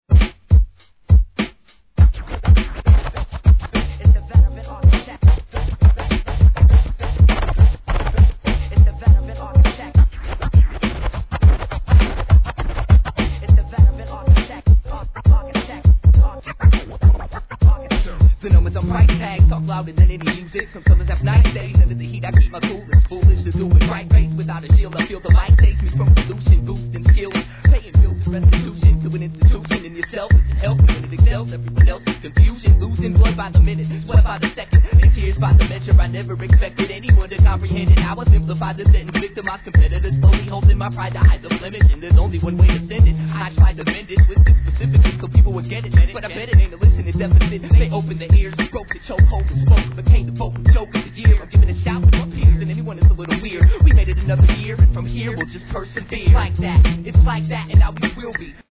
HIP HOP/R&B
シンプルなドラムにギターのみで創り上げる怒渋プロダクション!